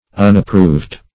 Unapproved \Un`ap*proved"\, a.